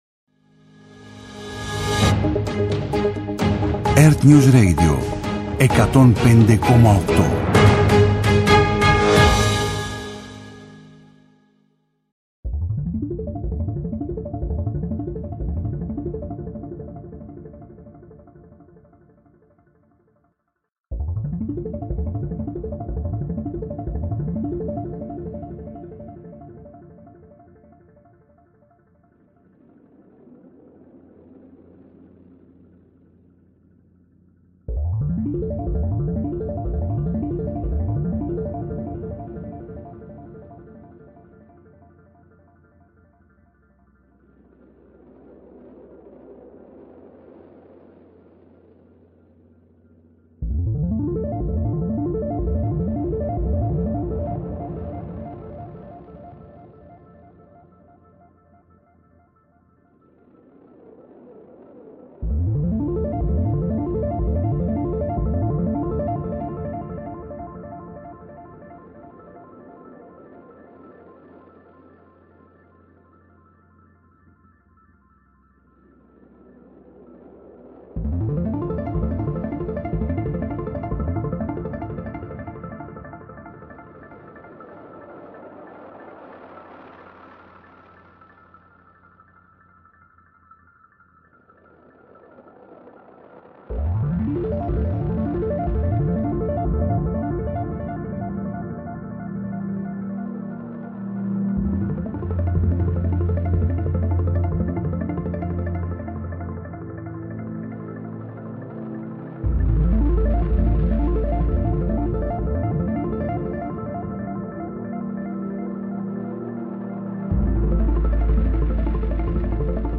Με τη νέα ραδιοφωνική του εκπομπή «Cine-Πώς», που θα μεταδίδεται κάθε Σάββατο στις 21.00, παρουσιάζει την cine-επικαιρότητα και επιχειρεί να λύσει – ή έστω να συζητήσει – τις κινηματογραφικές μας απορίες.